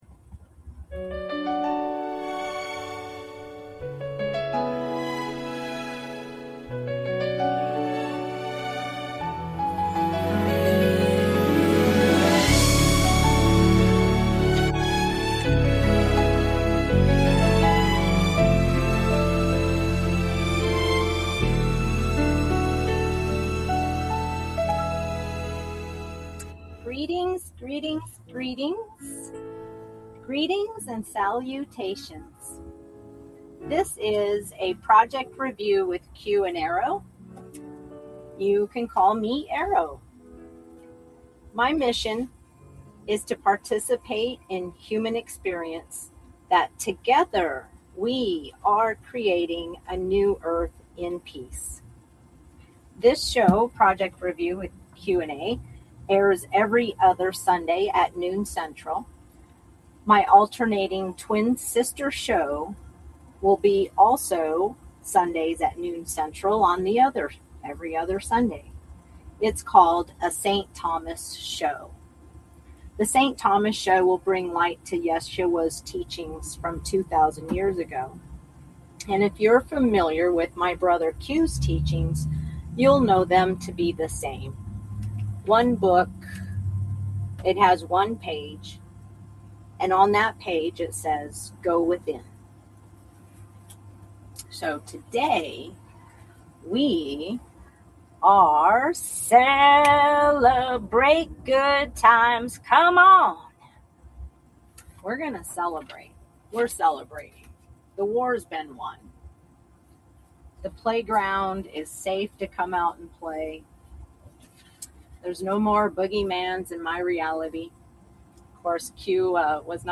Talk Show Episode, Audio Podcast, Project Review with Q n A and Empowering the Creator Within: Project Review and the Transition to New Earth on , show guests , about Empowering the Creator Within,Project Review and the Transition to New Earth,Creating the New Earth,through Heart-Centered Inspiration,you are a creative force from God,We are gods and goddesses,We are the creators,Mind Matrix,Heart Inspiration,Humanitarian Projects, categorized as Business,Education,Health & Lifestyle,Love & Relationships,Philosophy,Self Help,Society and Culture,Spiritual